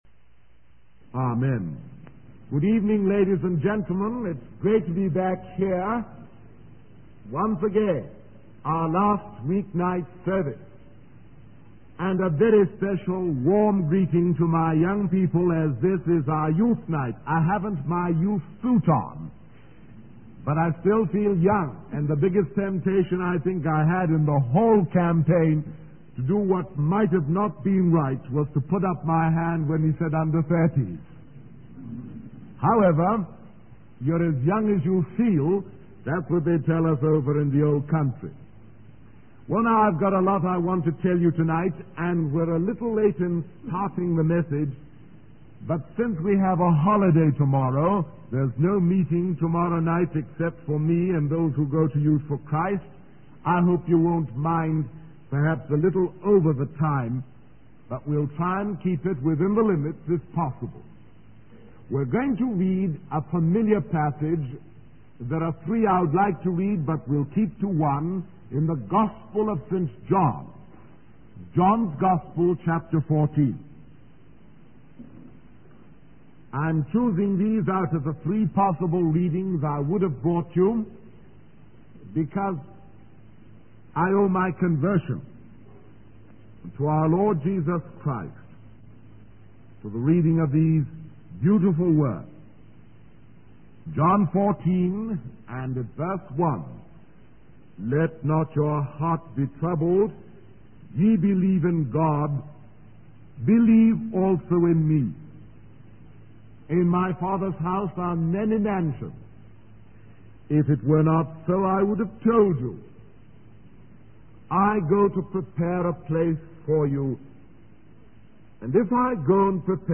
In this sermon, the preacher emphasizes the imminent return of Jesus Christ. He describes how this event will happen in a moment, as fast as the twinkling of an eye.
He urges the audience to be ready for this moment and reminds them that the end is near.